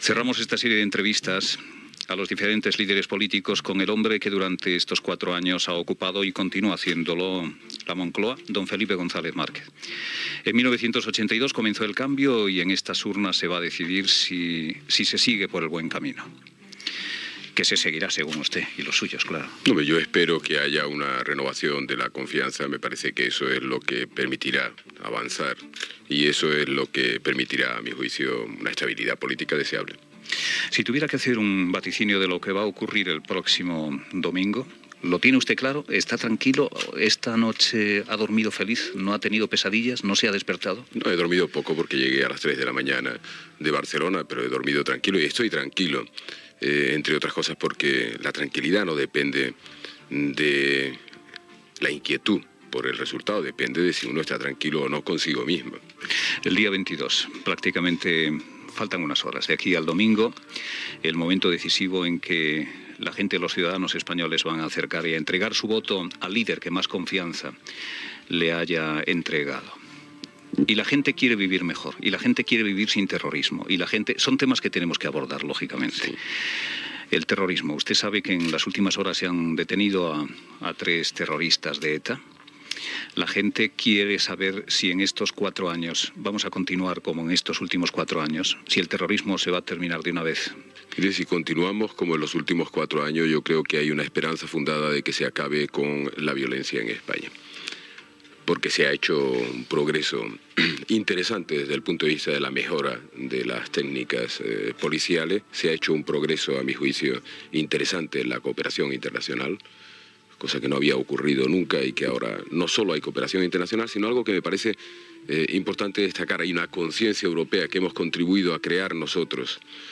Fragment d'una entrevista al president del govern espanyol Felipe González pocs dies abans de les eleccions generals de 1986 a les quals es tornava a presentar com a candidat del Partido Socialista Español.
Info-entreteniment